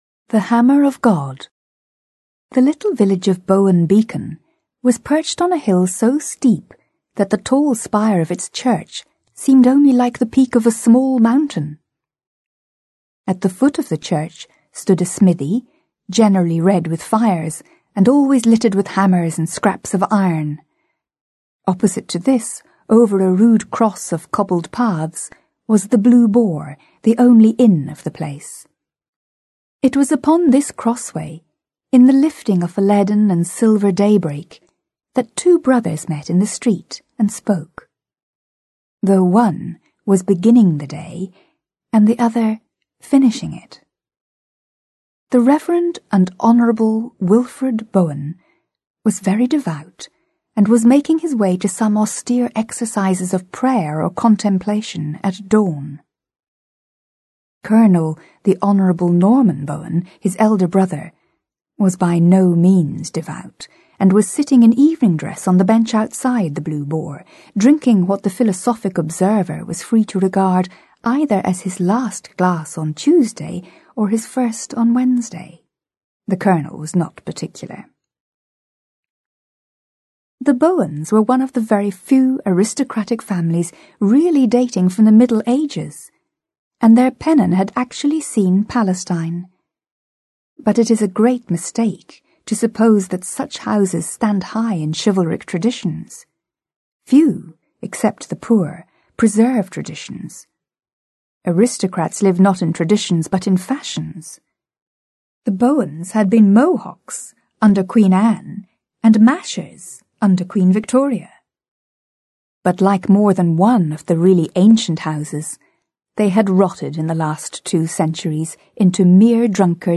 Аудиокнига Father Brown Stories | Библиотека аудиокниг